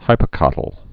(hīpə-kŏtl)